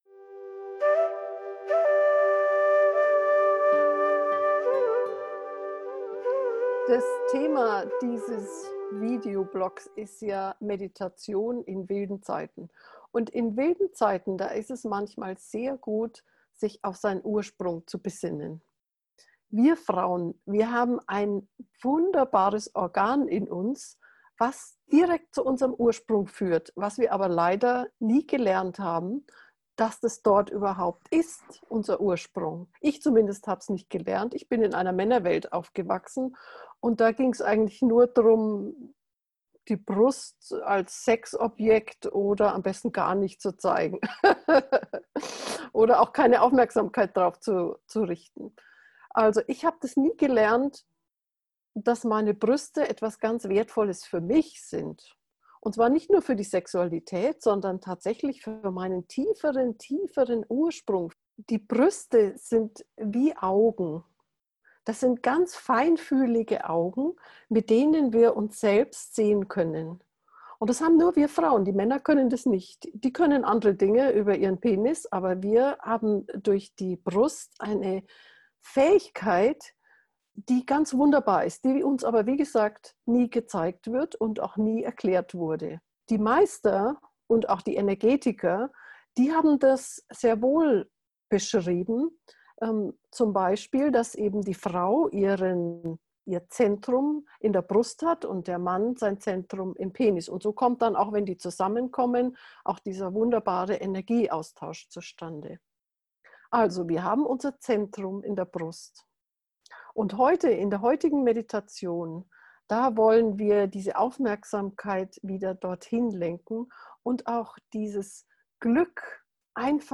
frauenmeditation-brueste-gefuehrte-meditation